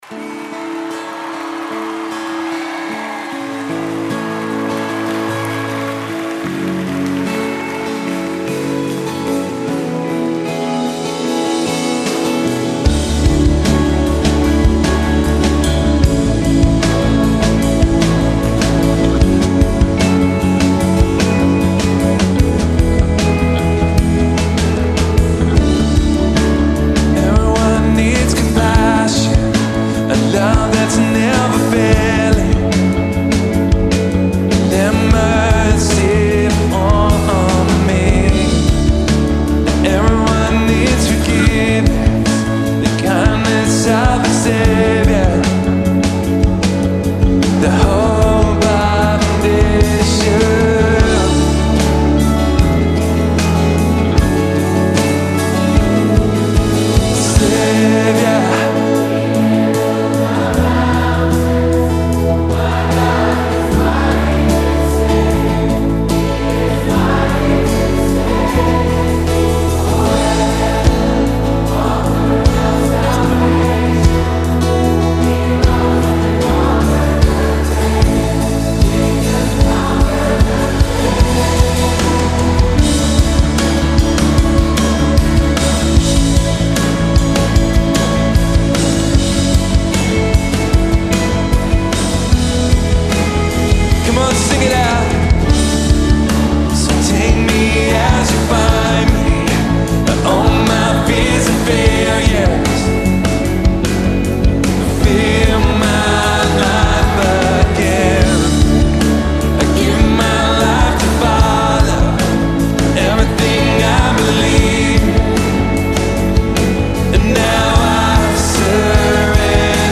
contemporary worship music
recorded live